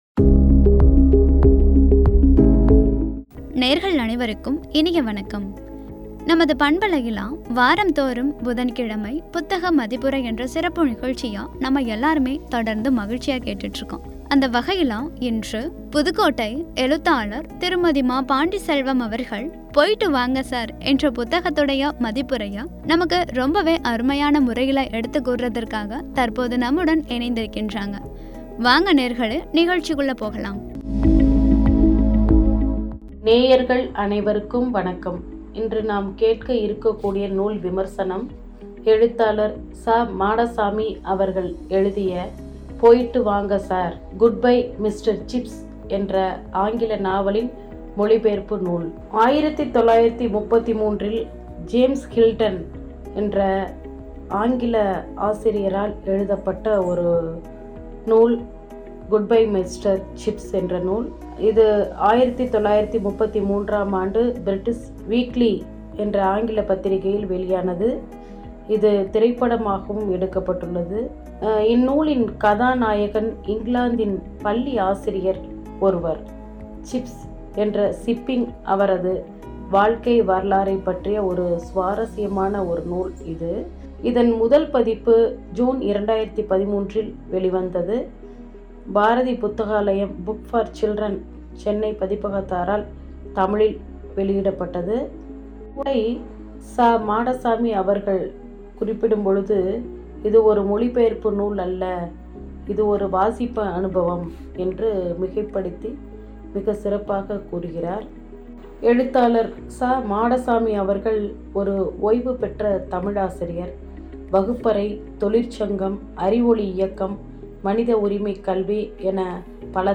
“போயிட்டு வாங்க சார்”(புத்தக மதிப்புரை) என்ற தலைப்பில் வழங்கிய உரை.